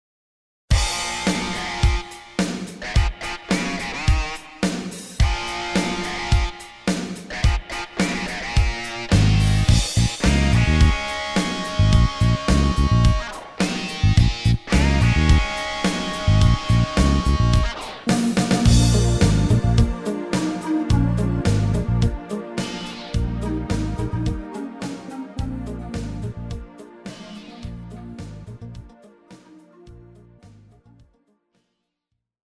KARAOKE/FORMÁT:
Žánr: Rock